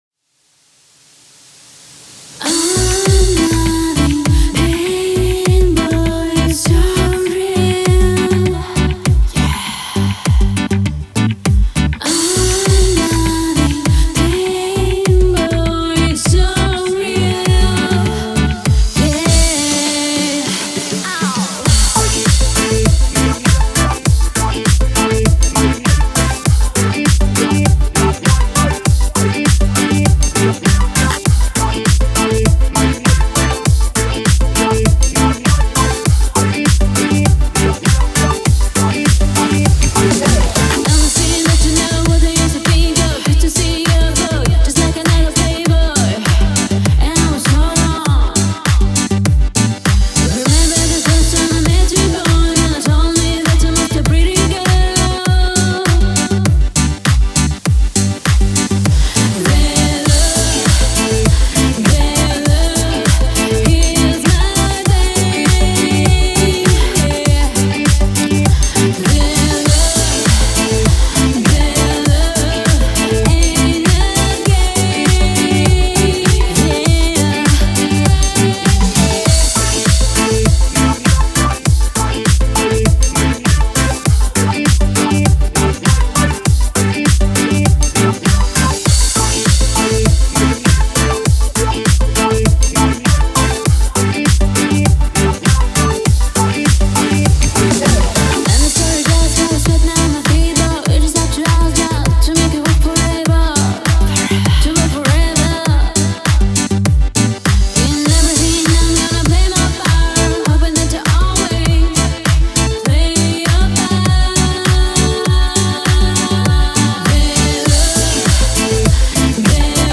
музыка попса